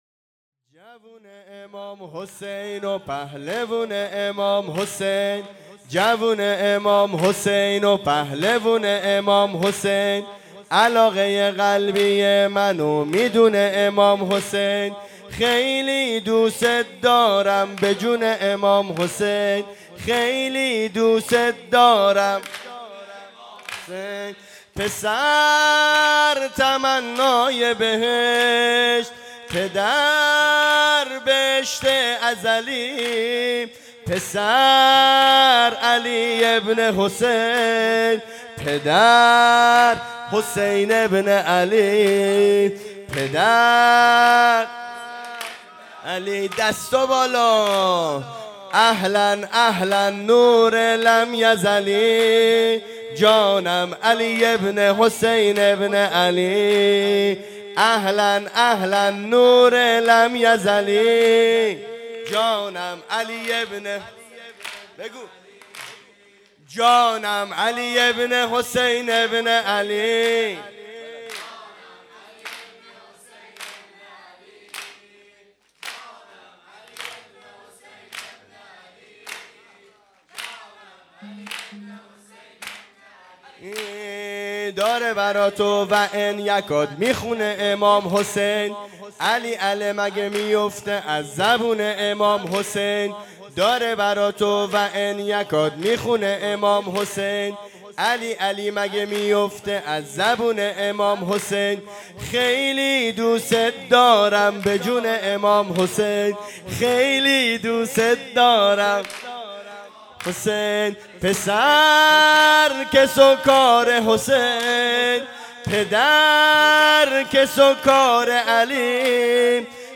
ویژه مراسم جشن بزرگ ولادت امام زمان(عج) و حضرت علی اکبر(ع) و جشن پیروزی انقلاب